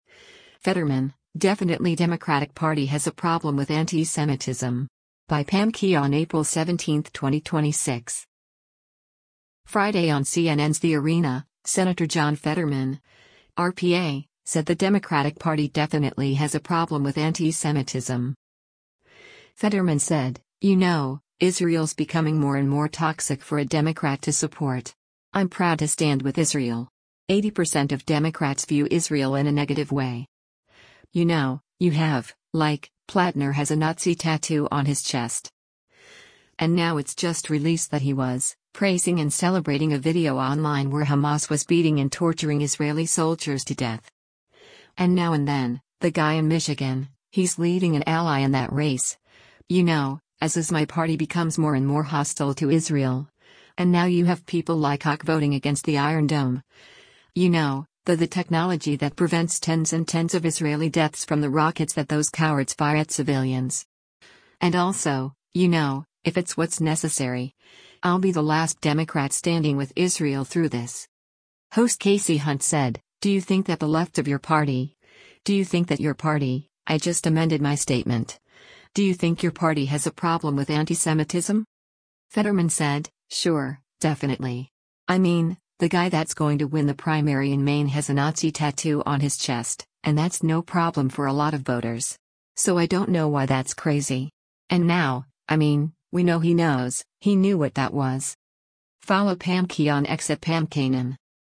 Friday on CNN’s “The Arena,” Sen. John Fetterman (R-PA) said the Democratic Party “definitely” has a problem with antisemitism.